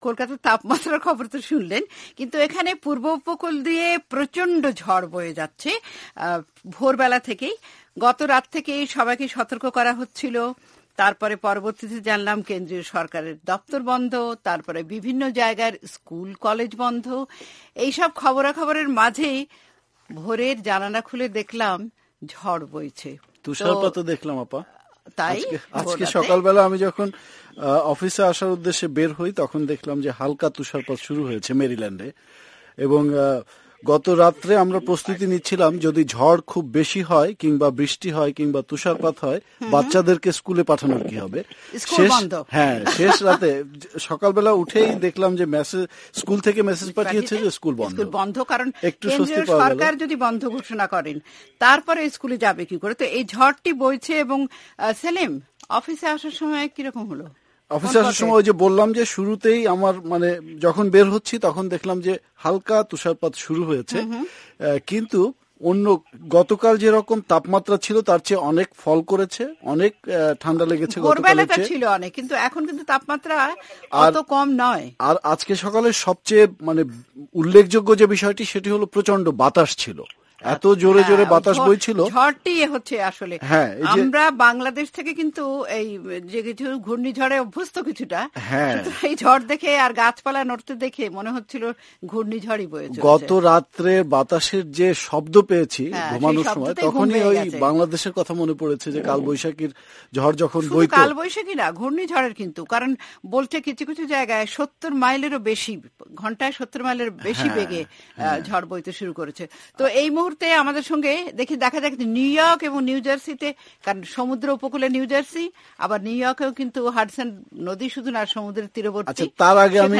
দেশের পূর্ব উপকুলীয় রাজ্য মেইন থেকে দক্ষিন-পূর্বাঞ্চলের নর্থ ক্যারোলাইনা পর্যন্ত প্রবল ঝড়, তুষারপাত ও ভারী বর্ষণের পূর্বাভাষ আগেই দিয়েছিল আবহাওয়া কর্তৃপক্ষ। শুক্রবার ভোর রাত থেকেই বিভিন্ন স্থানে ঝড় বৃষ্টি ও তুষারপাত শুরু হয়। নিউইয়র্ক নিউজার্সি ভার্জিনিয়া মেরীল্যান্ডের কোথায় কেমন আবহাওয়া আজ তা নিয়ে শুনুন এই আলোচনা।